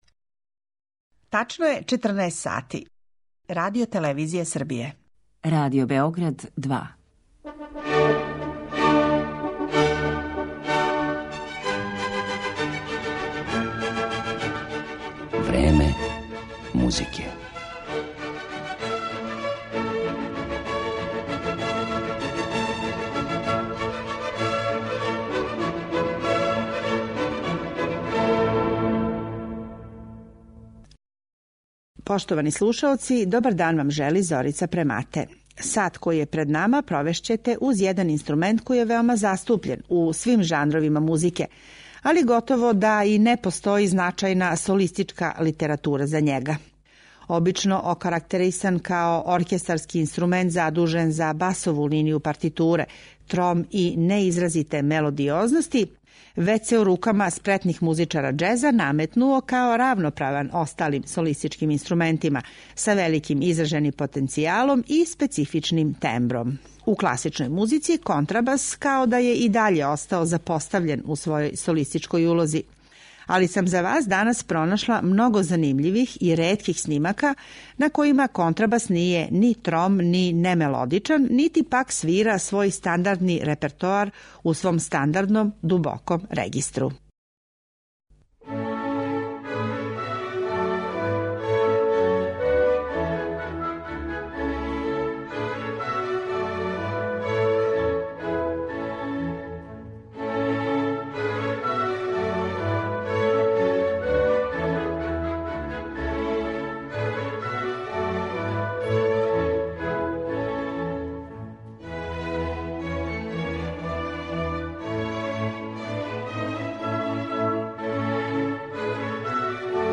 Ансамбл контрабаса Bassiona amorosa
Рани поподневни сат емисије Време музике провешћете уз један врлo необичан камерни састав који чине - само контрабаси.
приређена и аранжирана за камерне формације неколико контрабаса, са или без клавира.